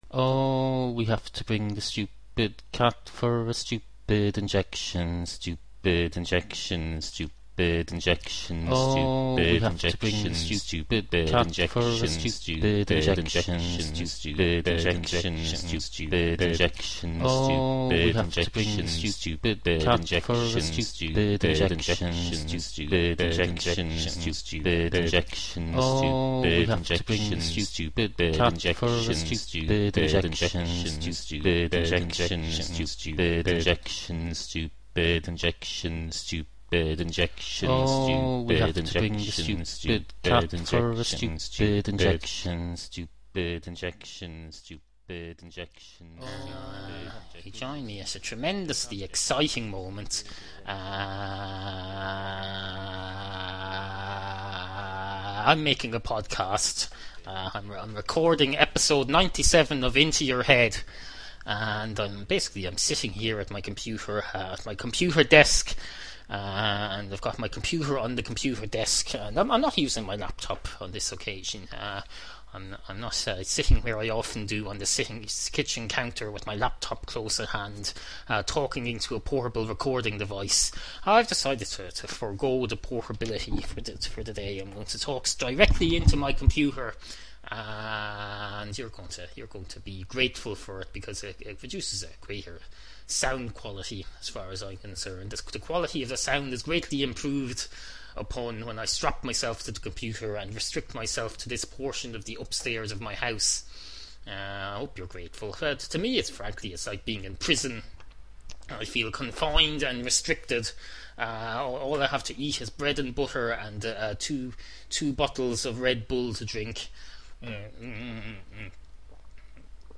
Obscure 21st Century Irish audio comedy series
Also: Three brand new acapello cat songs.